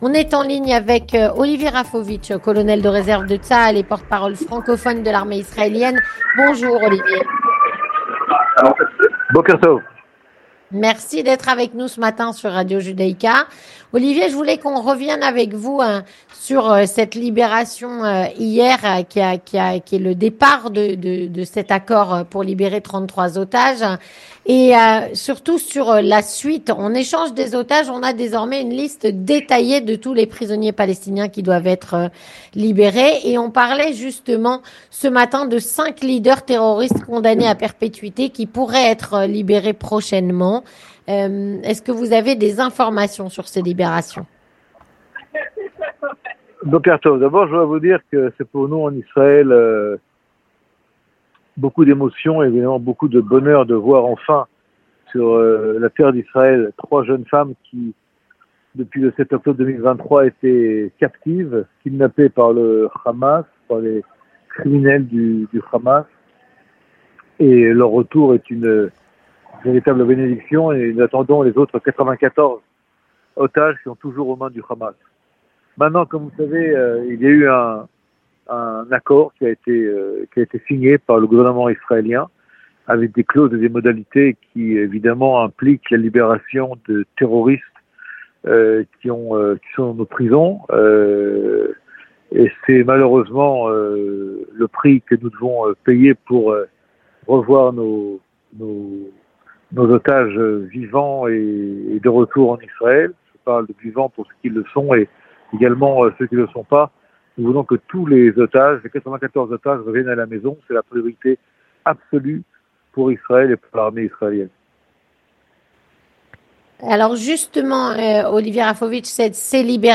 Édition Spéciale - Israël va libérer des condamnés à vie dans le cadre de l'accord avec le Hamas.